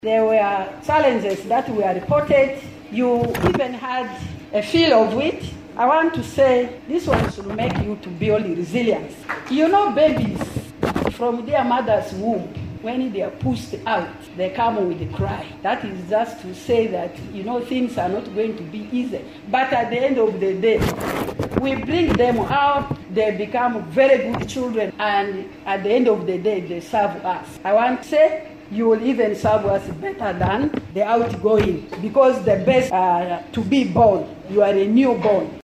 In a seamless handover ceremony held on Thursday, November 9th, 2023, the Arua City Clerk, Paul Batanda, officially passed the torch to his successor at the Arua City Council Hall. The outgoing city clerk, now transferred to Fort Portal city in Western Uganda, urged the new appointee, who had been serving in Mbale city in eastern Uganda, to build upon the existing foundations and address any weaknesses.